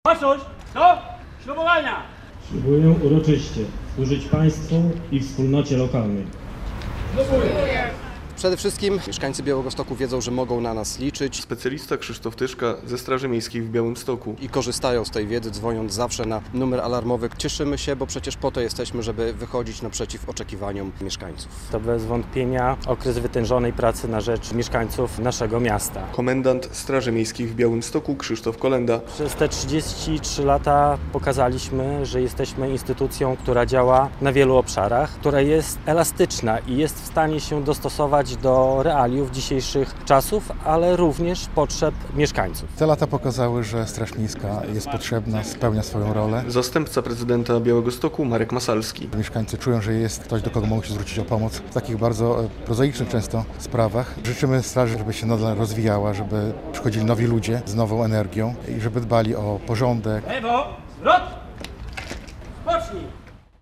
W czwartek (29.08) obchodzili swoje święto w rocznicę powołania Straży Miejskiej w Białymstoku. Podczas uroczystości przed Pałacykiem Gościnnym Branickich przy Kilińskiego strażnicy odbierali awanse, a aplikanci złożyli ślubowanie.
relacja